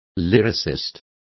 Complete with pronunciation of the translation of lyricist.